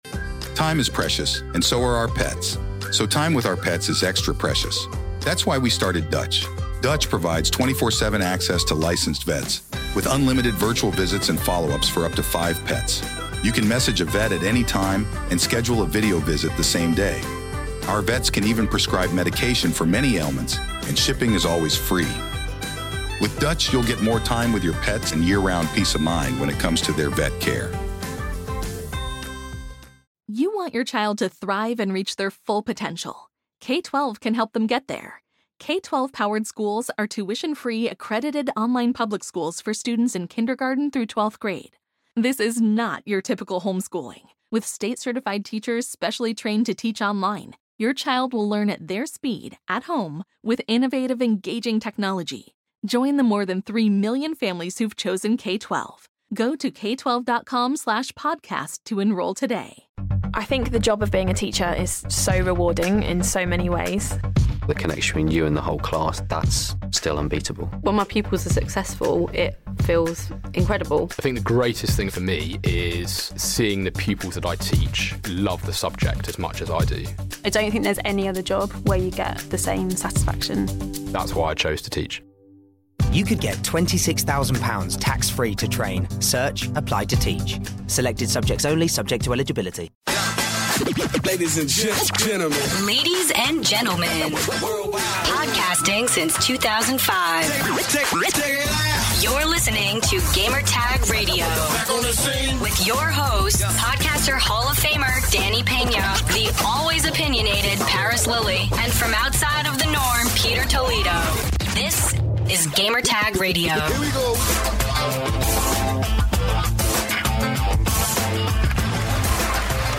Episode #655 - Interview with AJ Mendez Brooks
Podcast interview with former WWE women's champion, AJ Mendez Brooks about her new book, Crazy Is My Superpower. She talked about her personal life, wrestling and video games.